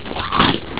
munch.au